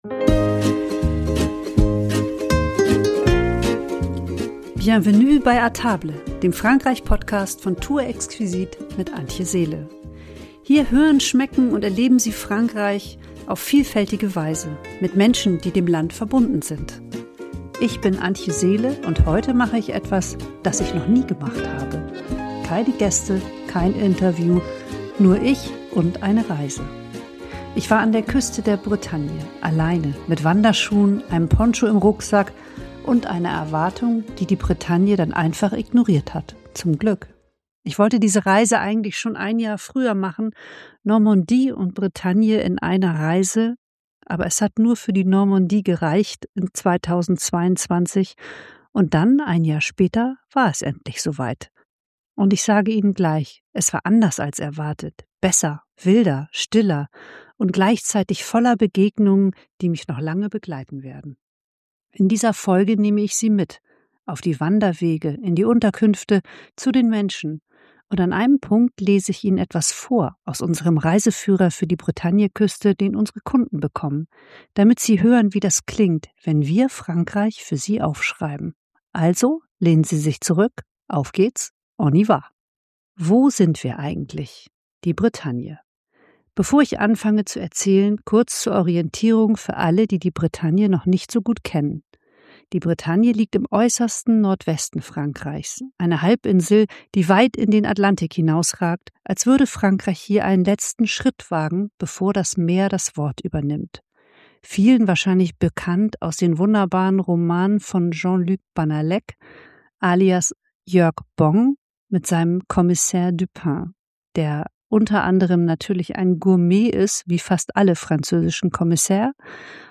Diese Folge ist bewusst persönlicher – wie ein Gespräch bei einem guten Glas Wein.